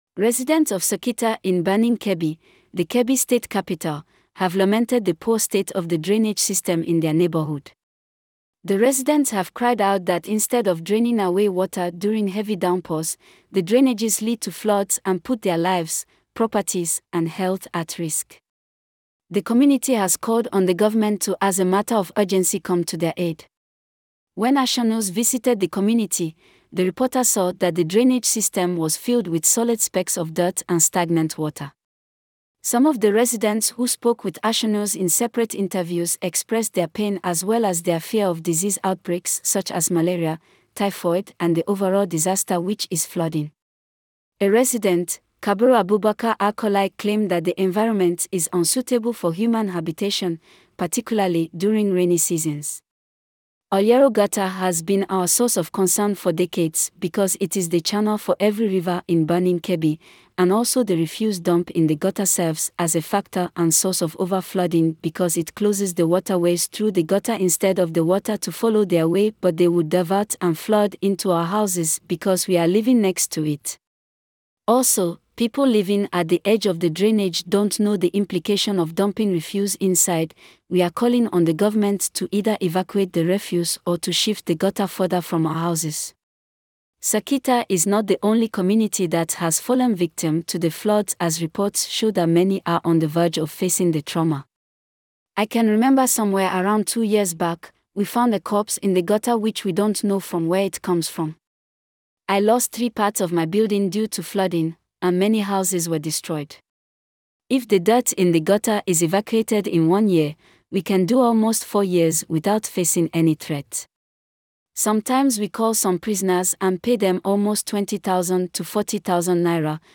Some of the residents who spoke with ASHENEWS in separate interviews expressed their pain as well as their fear of disease outbreaks such as malaria, typhoid and the overall disaster which is flooding.